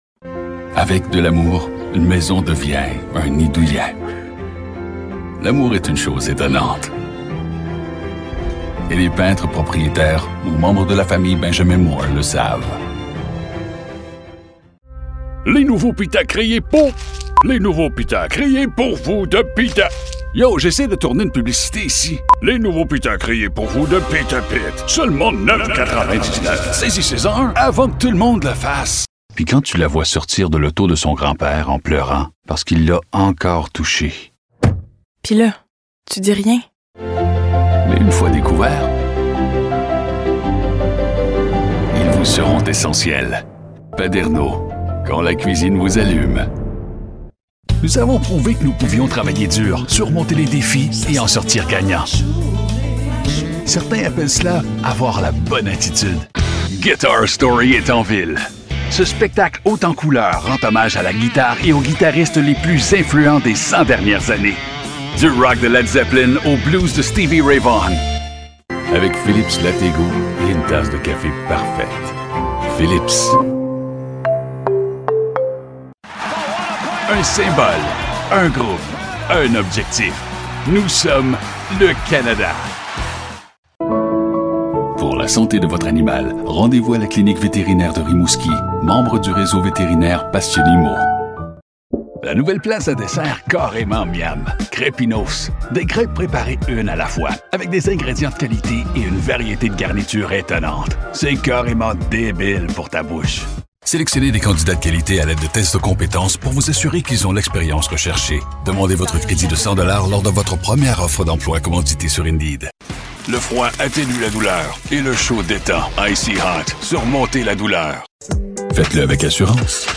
Canadian French - French Canadian